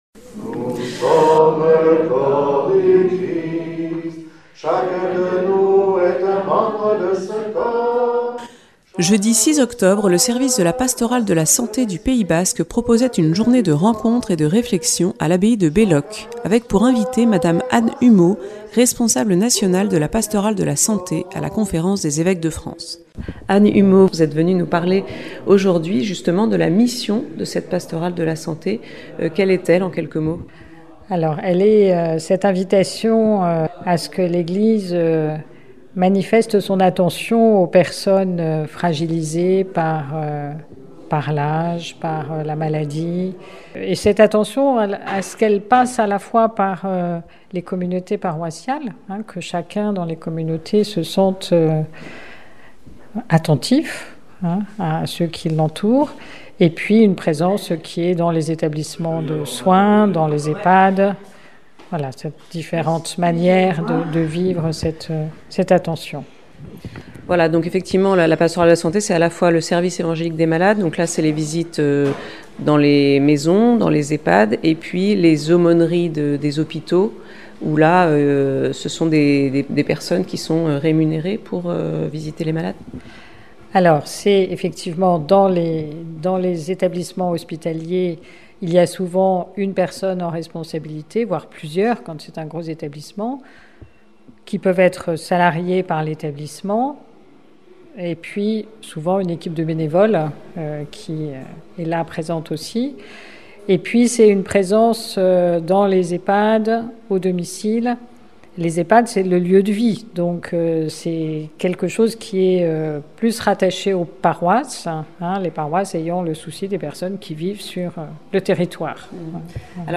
Accueil \ Emissions \ Infos \ Interviews et reportages \ La journée de la Pastorale de la Santé à Belloc le 6 octobre : (...)